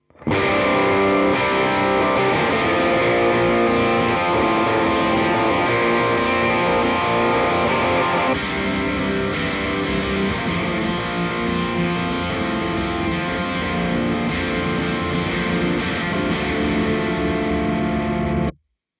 No EQ, no compression, no reverb, just the guitar plugged into the audio interface.
Now let’s test the SHOD for the heavy tones combined with modulation pedals.
In the first part of the audio sample you hear the Deluxe1 and in the second part you hear the TwinR.
Heavy Tone
Heavy.mp3